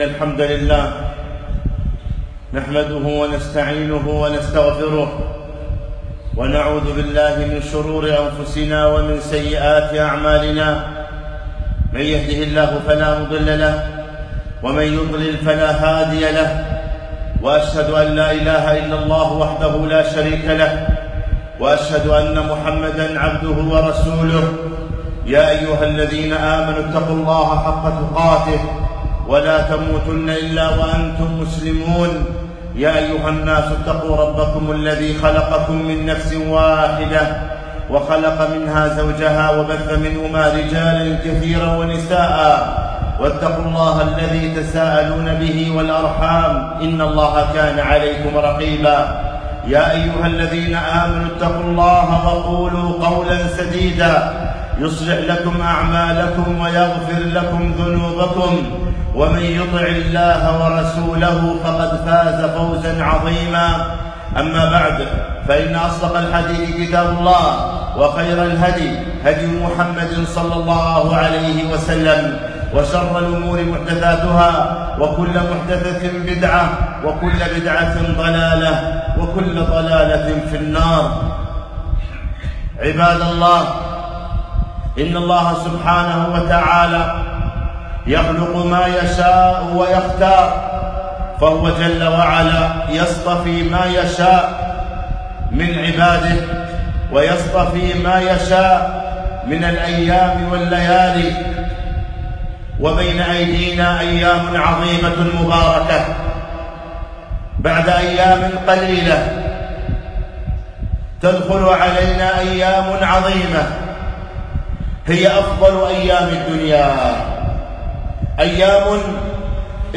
خطبة - عشر ذي الحجة فضائل وأحكام